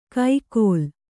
♪ kai kōl